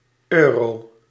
Ääntäminen
Ääntäminen Tuntematon aksentti: IPA: /ˈøːroː/ Haettu sana löytyi näillä lähdekielillä: hollanti Käännös 1. euro {en} Esimerkit Die broek kost tachtig eurie .